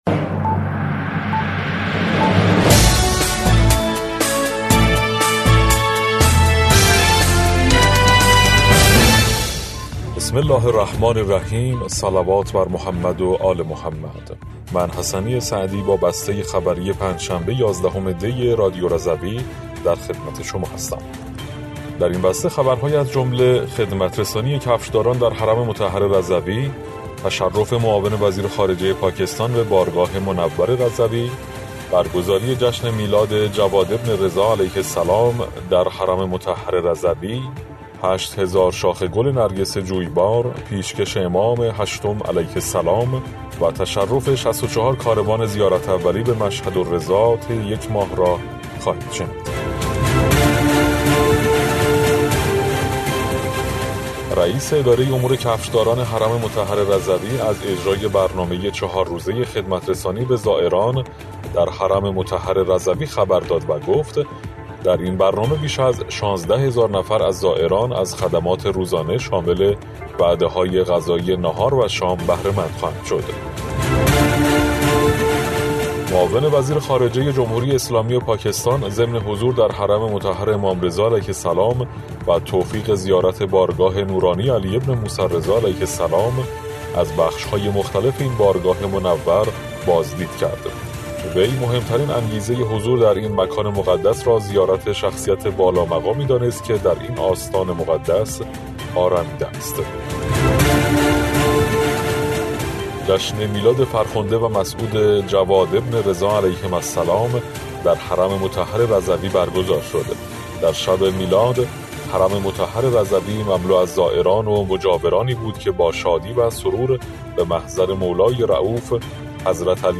بسته خبری ۱۱ دی ۱۴۰۴ رادیو رضوی؛